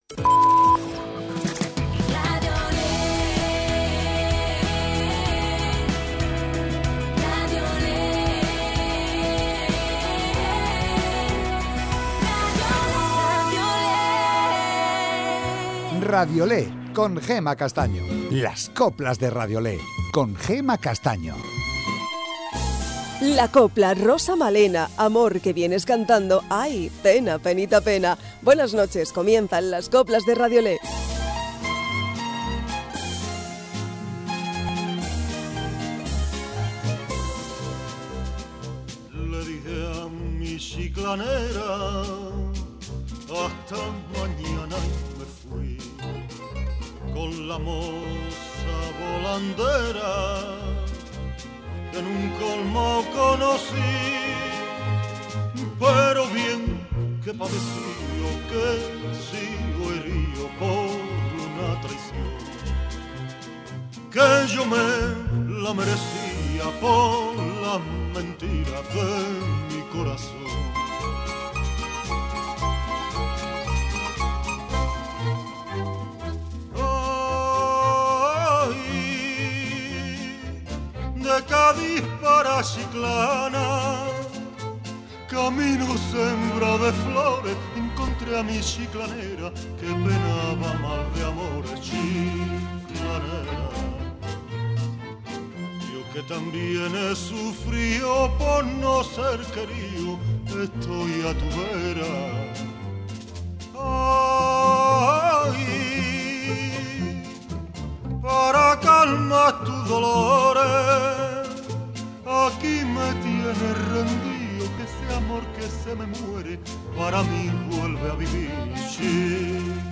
Las mejoras coplas de hoy y de siempre